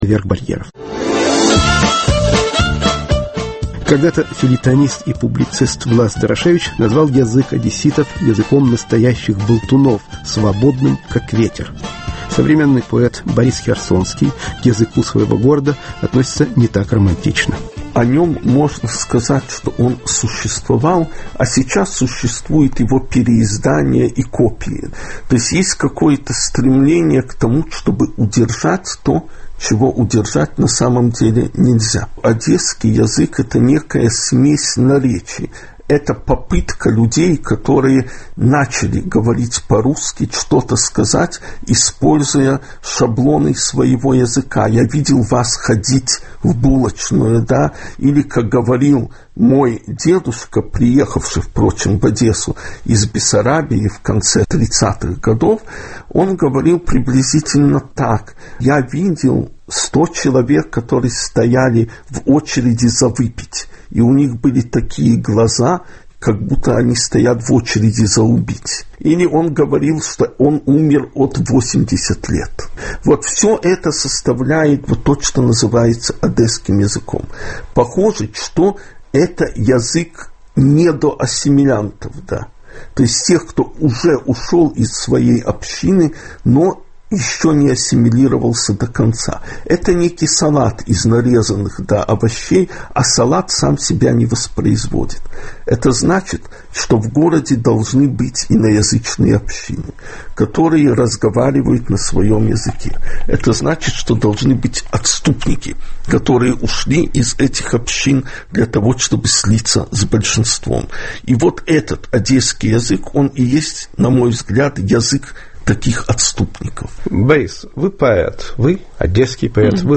Беседа с поэтом Борисом Херсонским.